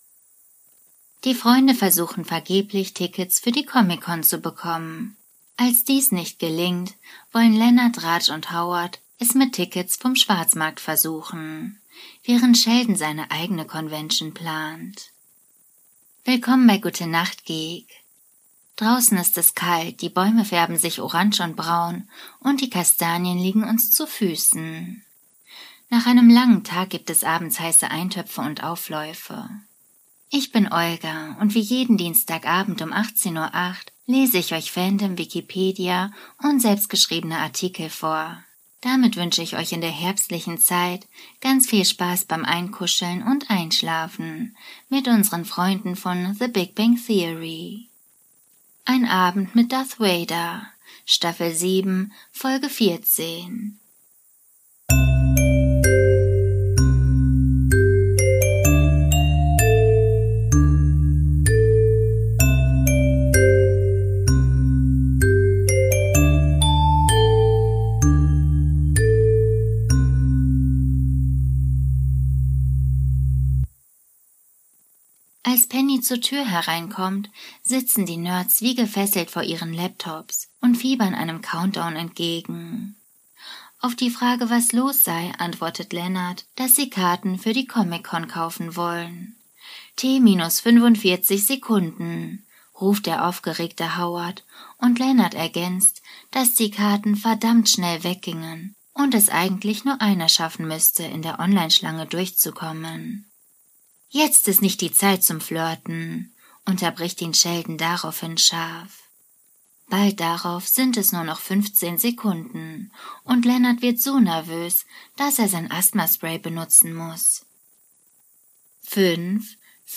Audio Drama
Vorlesung